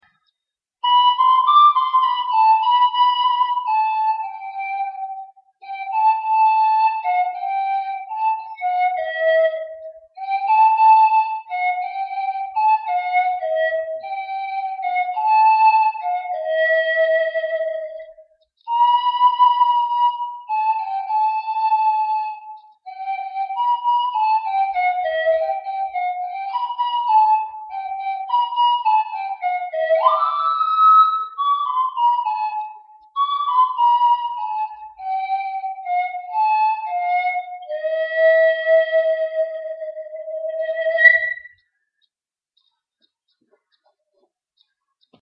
Пан-флейта Gibonus FPS-Alto Cherry
Пан-флейта Gibonus FPS-Alto Cherry Тональность: G
Профессиональный альт, предназначенный для выступлений. Изготовлен из пропитанной древесины вишни.
Диапазон - три октавы (G1-G4), строй диатонический.